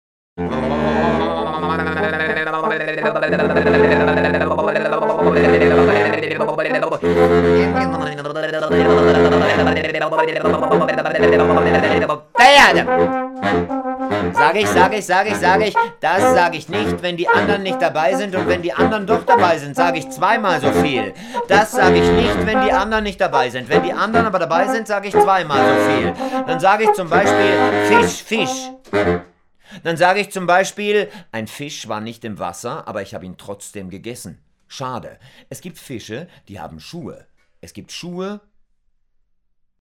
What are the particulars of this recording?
field recordings at SuperGau-Festival Lungau (May 2023)